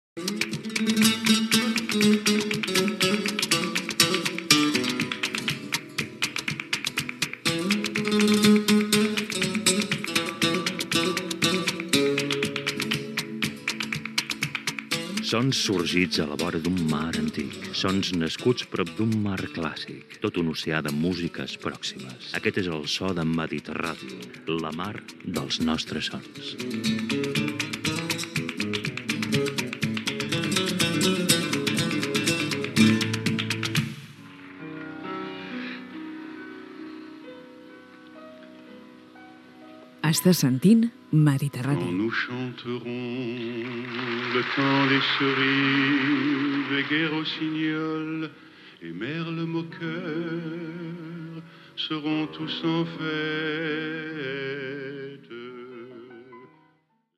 Indicatiu de l 'emissora i tema musical